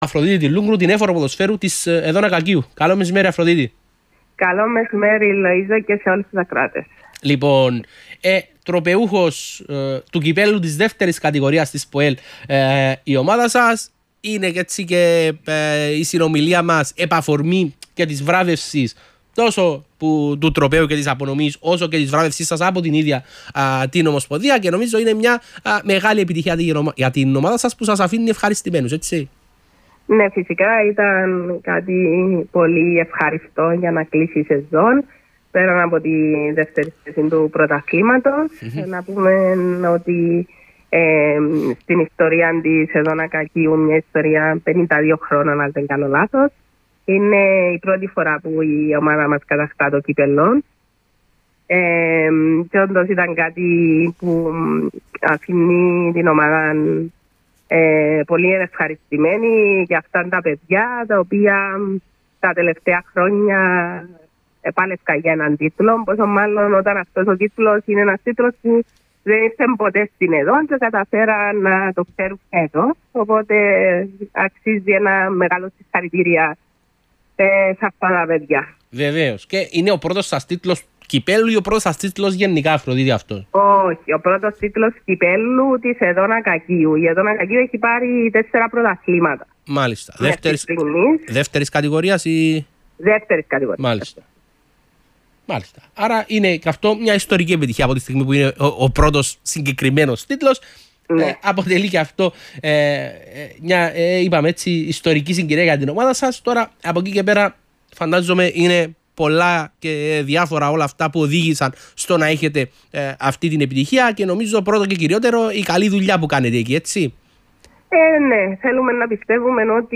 ραδιοφωνικές δηλώσεις